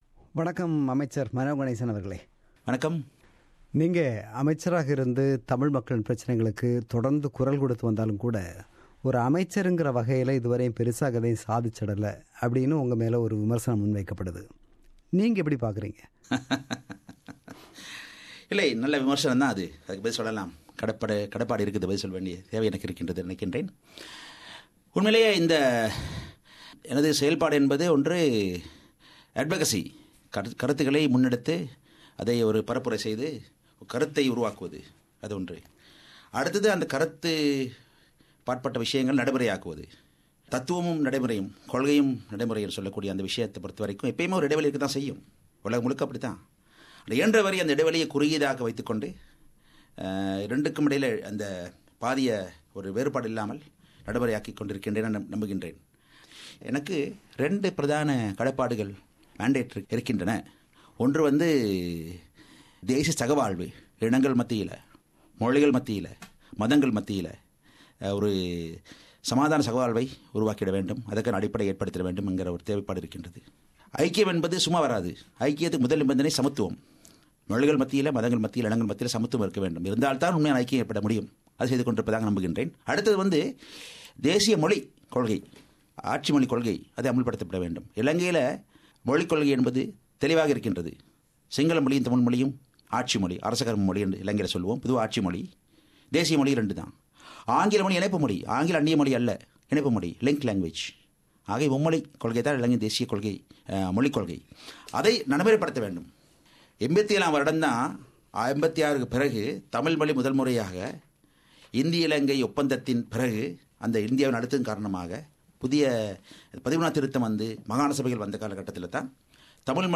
Interview with Minister Mano Ganesan – Part 1